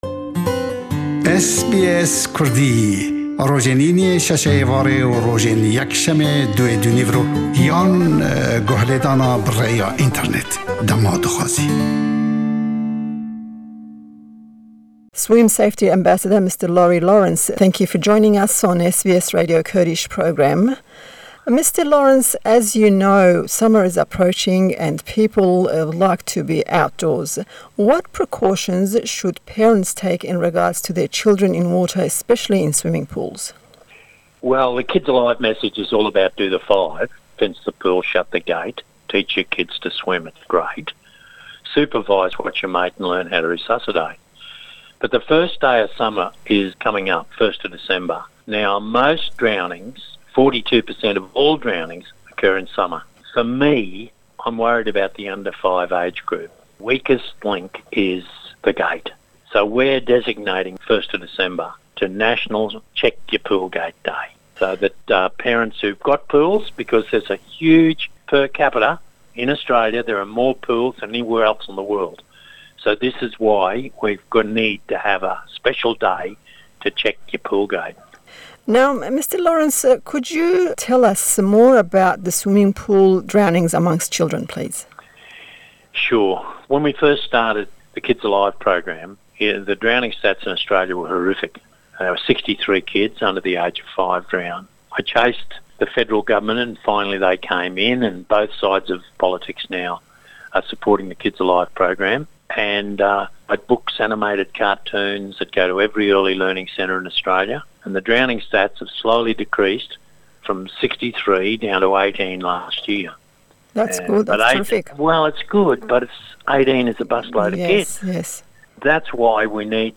1î Dîsamber Rojî Niştimanî ye be Çawdêrî Dergayî Melewanga, National Check-Your-Pool-Gate Day- be em bone ye we ême em lêdwaney xwareweman amade kirdû we le gell Berêz Laurie Lawrence, ke werzişwanêkî mezinî Australya û êsta ballwêzî melewanî asayişe le Austalya, ke hawkat damezrênerî rêkxirawey Kids Alive e.
Laurie Lawrence, Swim Safety Ambassador.